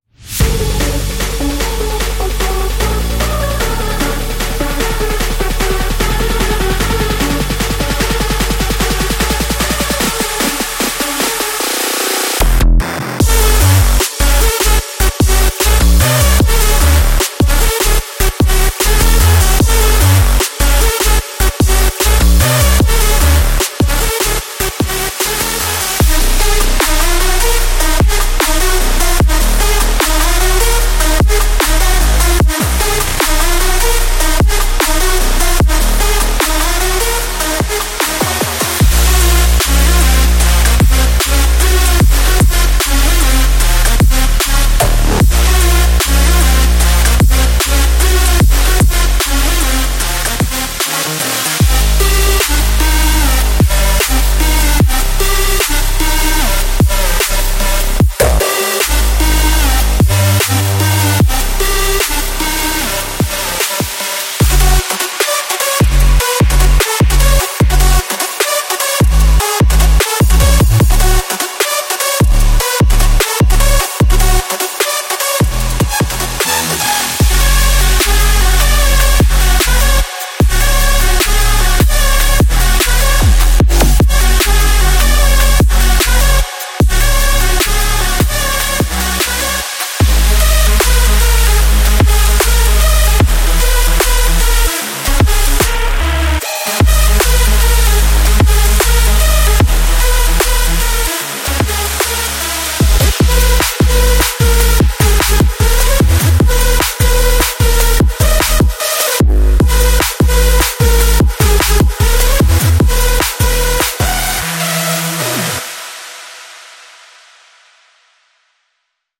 سمپل پک هارد ویو